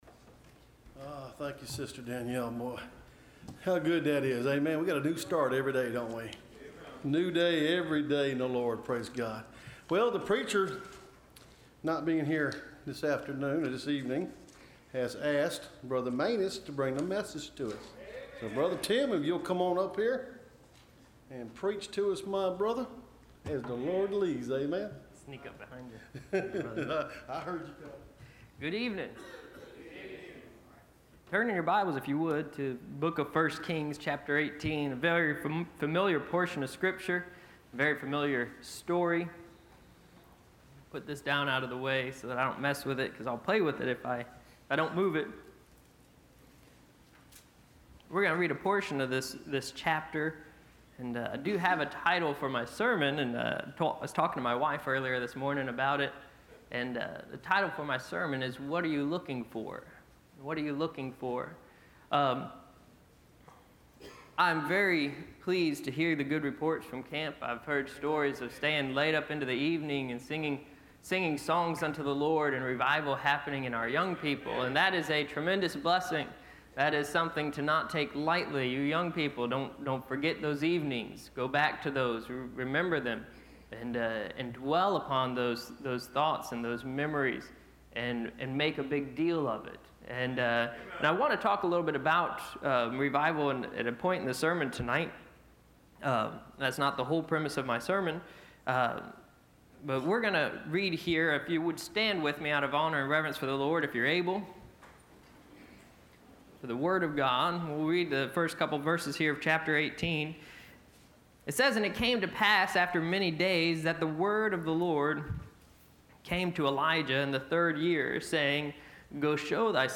Listen to Message
Service Type: Sunday Evening